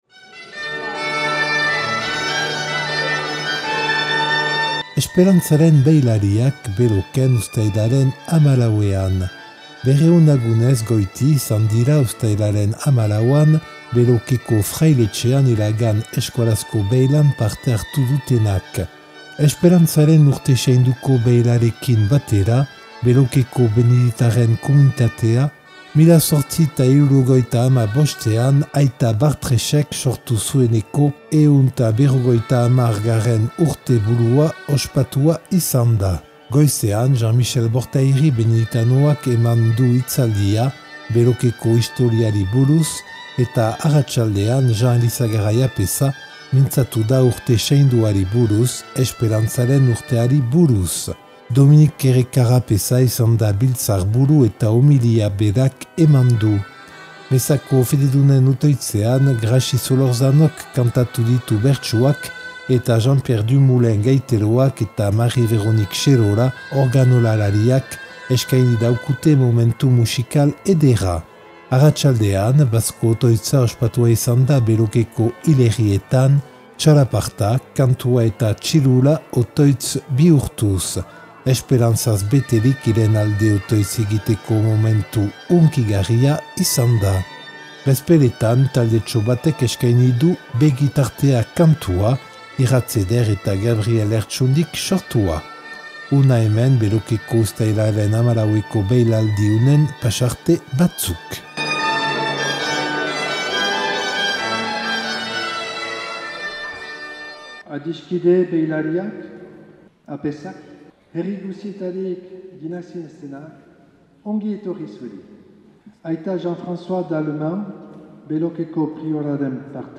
200 lagunez goiti izan dira 2025ko uztailaren 14an Belokeko frailetxean iragan euskarazko beilan parte hartu dutenak.
Elkarrizketak eta erreportaiak